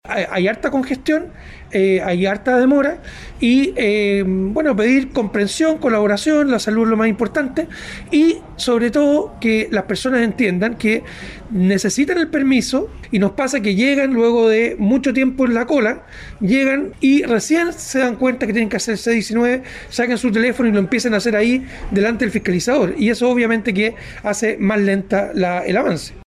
Así lo dijo el intendente metropolitano, Felipe Guevara.